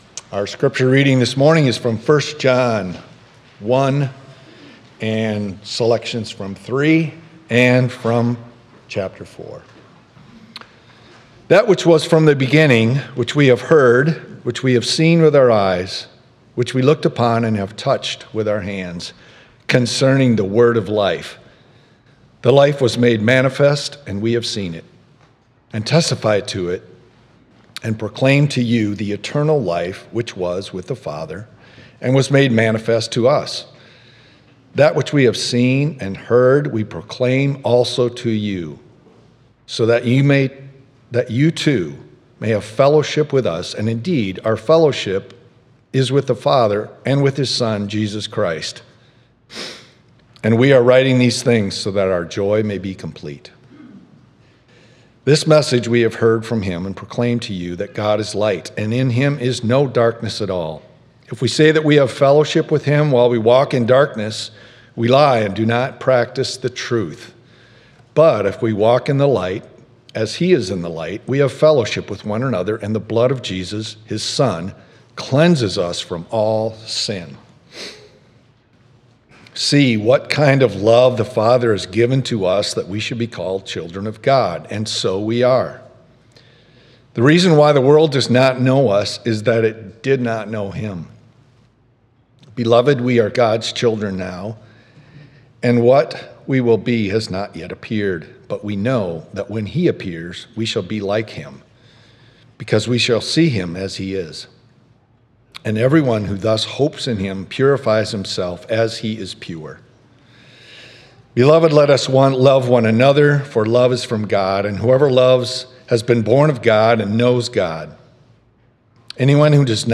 9.14.25 Sermon.m4a